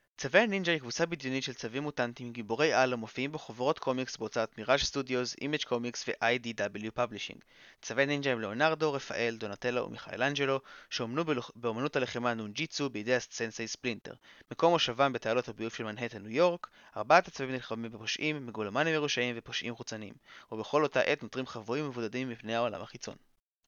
באשר לאיכות הסאונד, על מנת לבדוק באמת את יכולות המיקרופון, הכנתי הקלטה קטנה בה אני משווה את ה-HyperX SoloCast למיקרופון שנמצא על גבי אוזניות הגיימינג Corsair Void Pro RGB Wireless וכן ל-Razer Seiren Mini, הנכם מוזמנים להקשיב לכל אחת מין ההקלטות שכאן ולהחליט באופן אישי איזה מיקרופון מספק את הסאונד האיכותי ביותר: